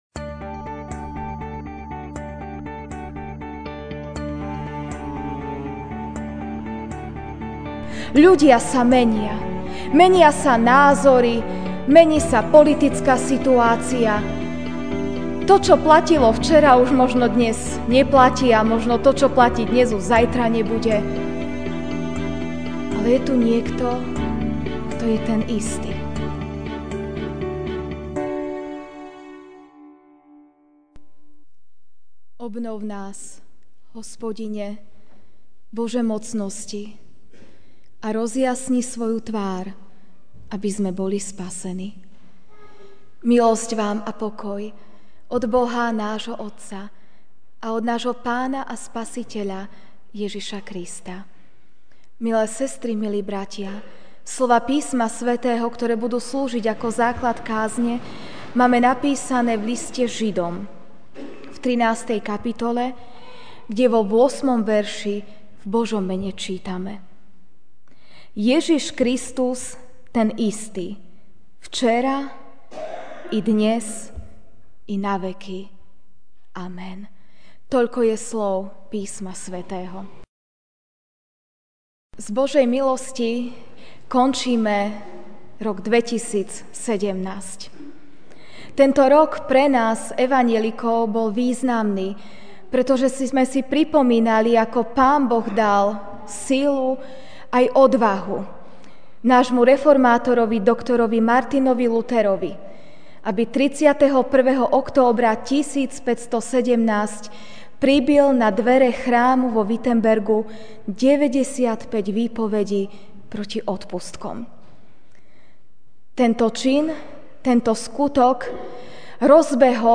Večerná kázeň